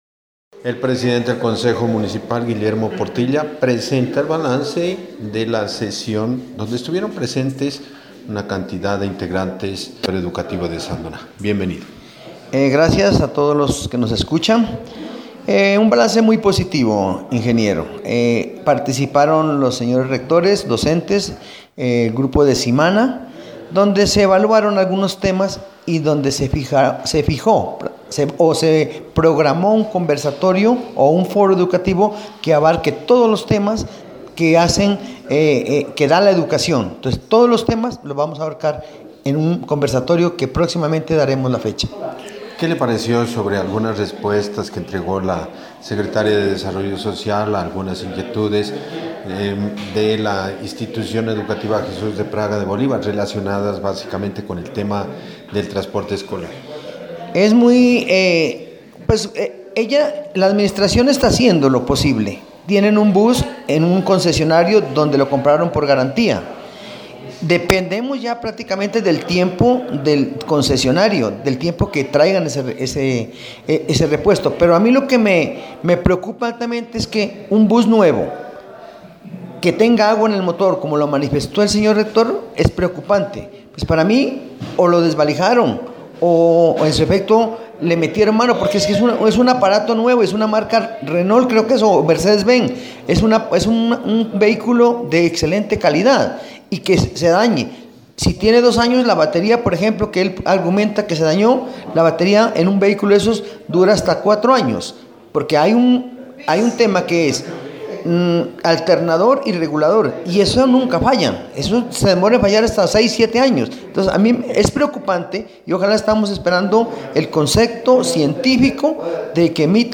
Entrevistas:
Presidente del Concejo Guillermo Portilla